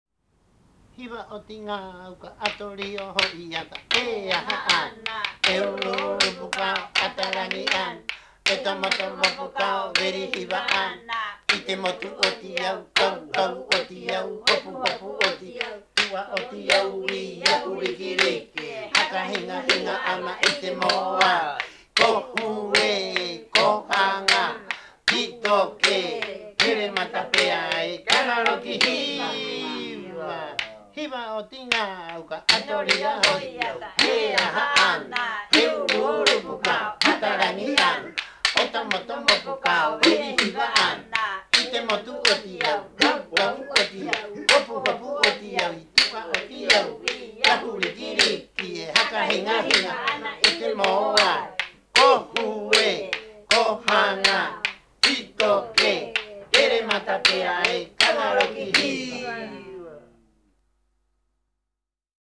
Canción de Papa Iko (tradicional pascuense)